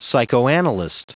Prononciation audio / Fichier audio de PSYCHOANALYST en anglais
Prononciation du mot psychoanalyst en anglais (fichier audio)